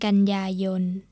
kan-ya-yon normal tone.